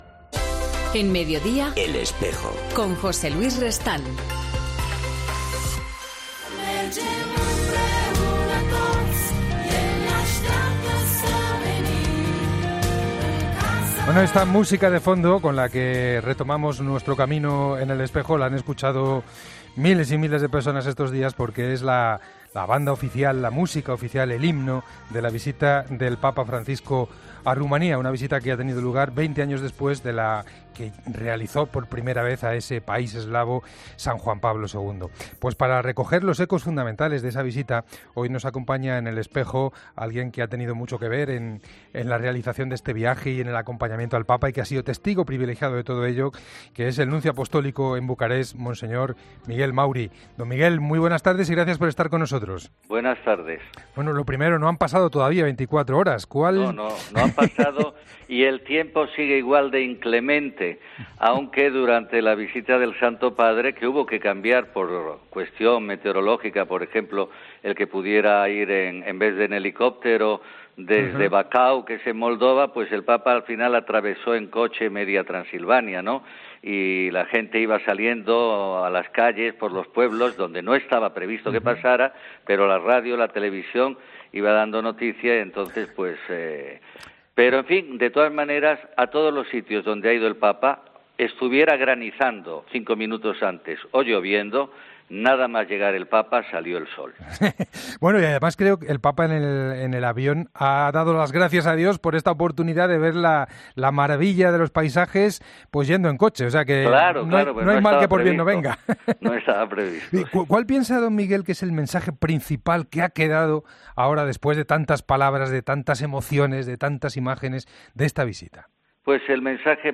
Diálogos EN 'EL ESPEJO' Mons.